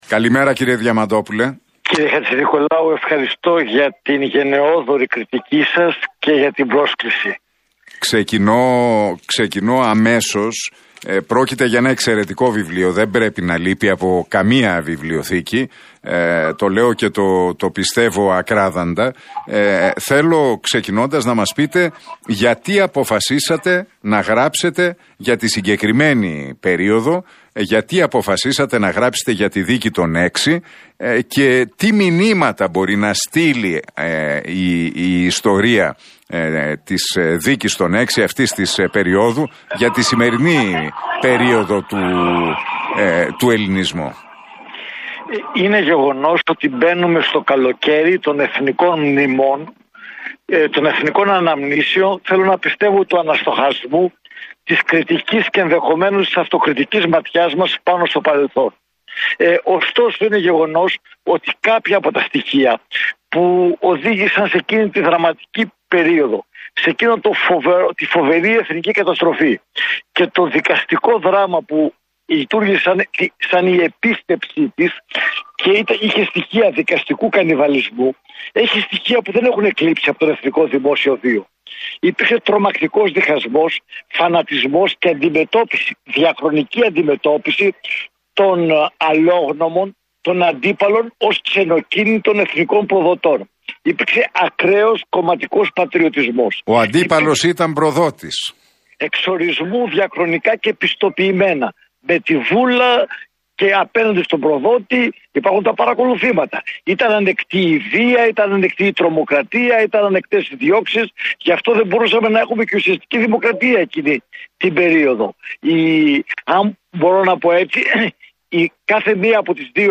μίλησε στον Realfm 97,8 και τον Νίκο Χατζηνικολάου